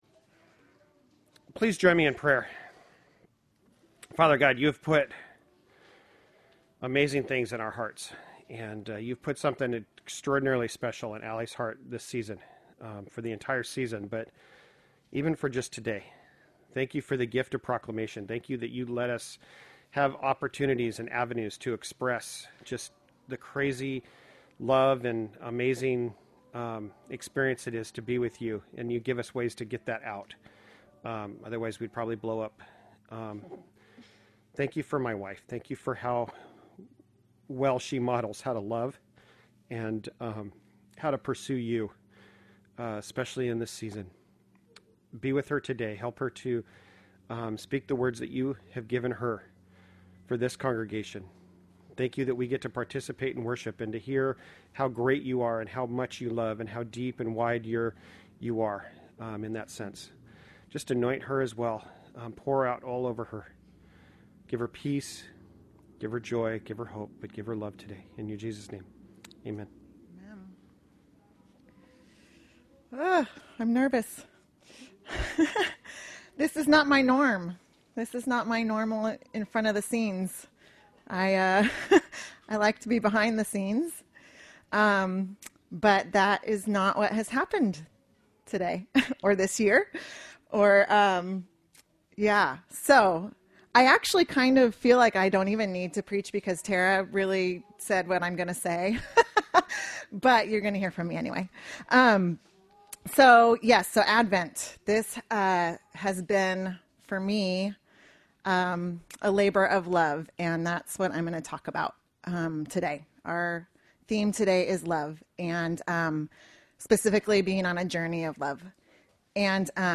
Service Type: Sunday Morning Related « Give Thanks